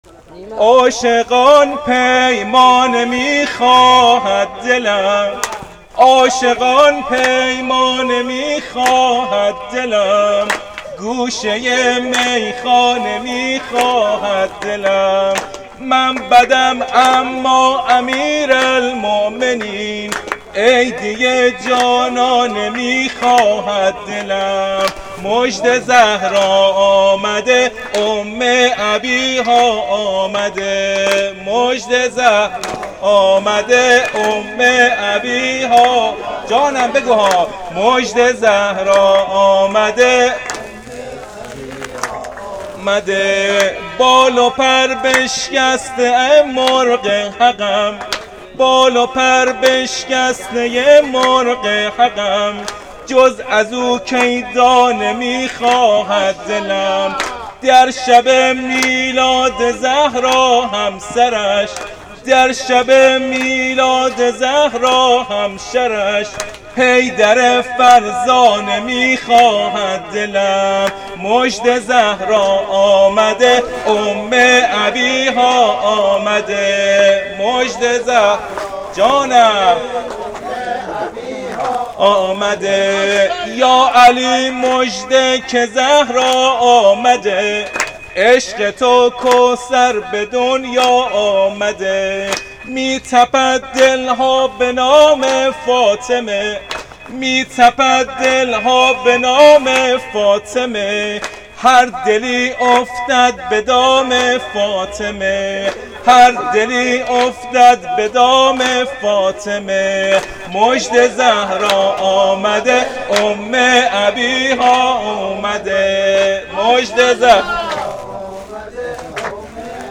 سرود مژده زهرا اومده